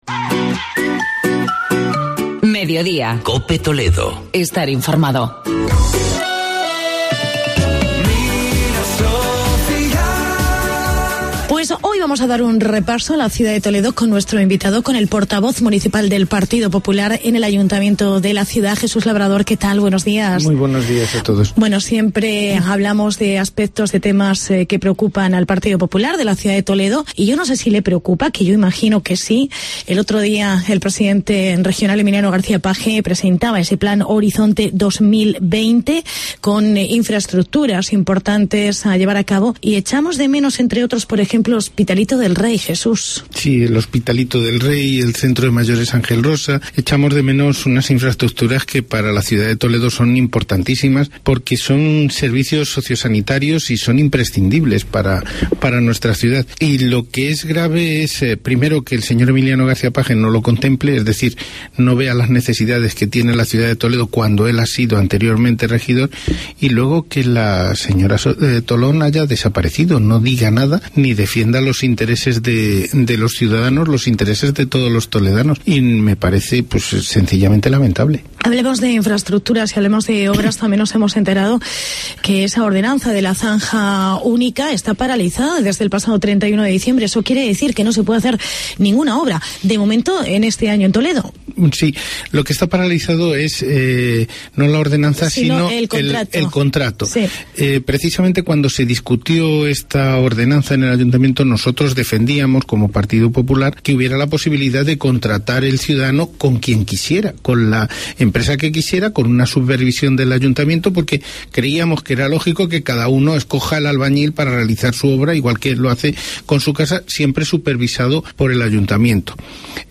Entrevista con Jesús Labrador, portavoz municipal del PP en Toledo